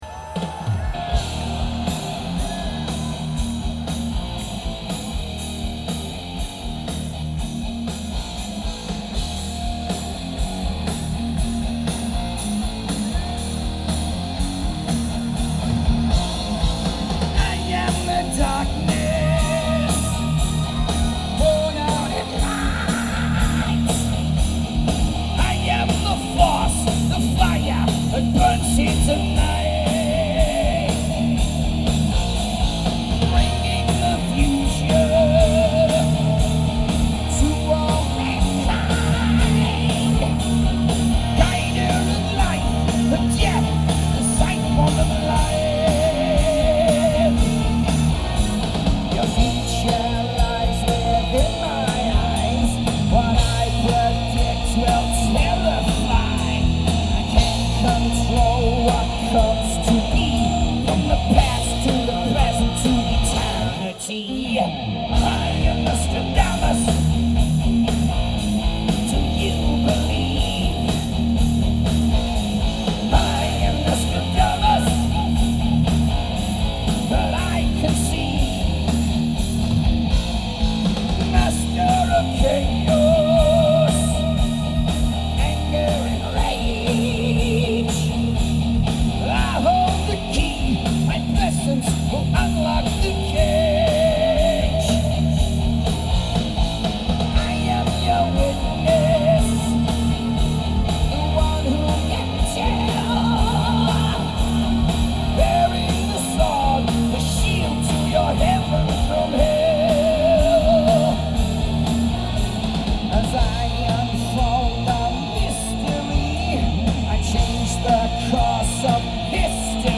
quality here is very good.